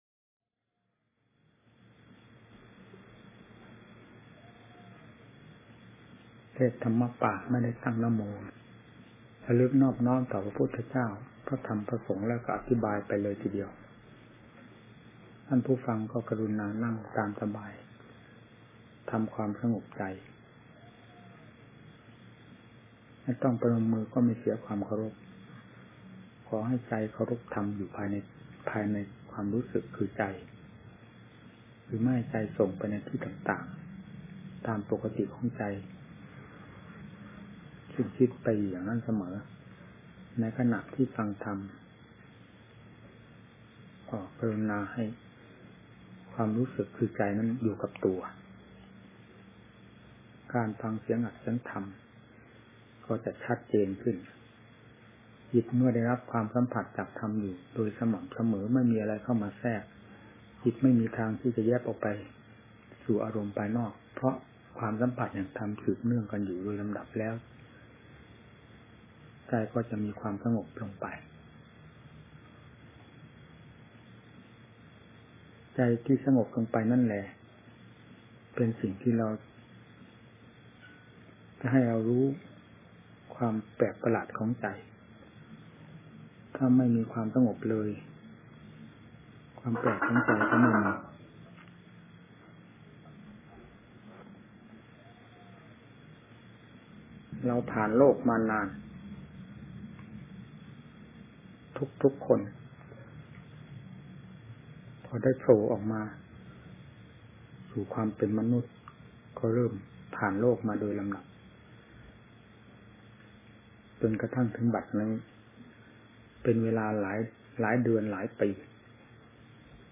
พระธรรมวิสุทธิมงคล (บัว ญาณสมฺปนฺโน) - พระธรรมเตรียมพร้อม ชุดที่ ๑ - เสียงธรรม : ธรรมะไทย